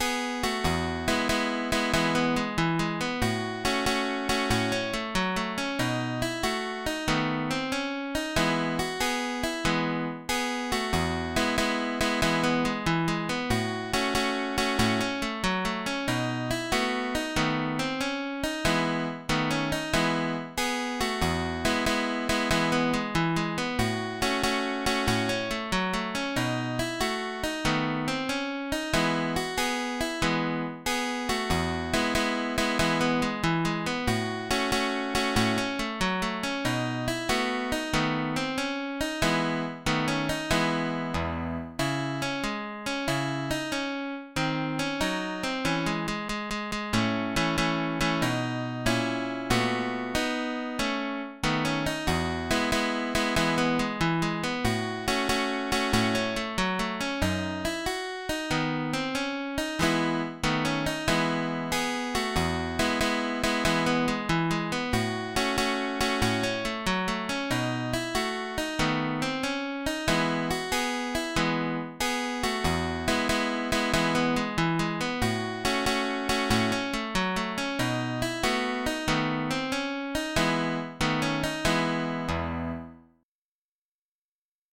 ALMA MARCH è l’immancabile marcetta stile “yankee“.